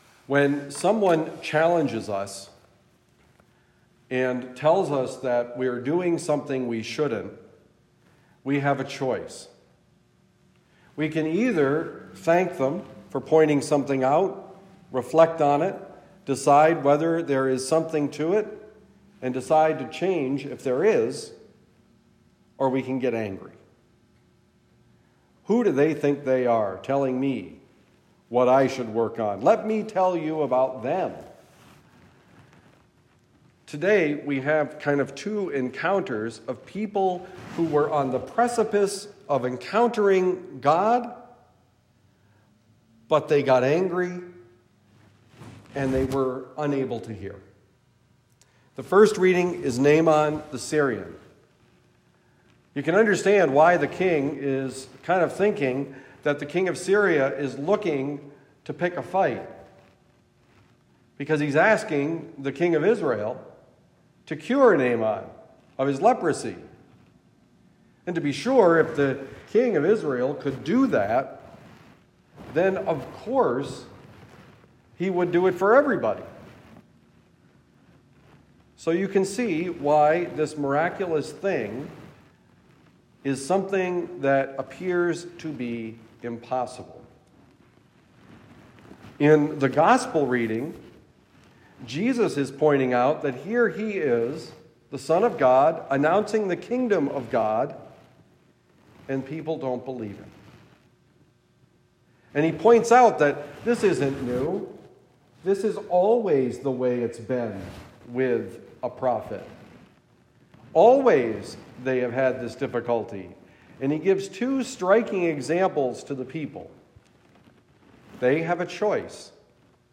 When you are wrong: Homily for Monday, March 13, 2023
Given at Our Lady of Providence, Winooski, Vermont.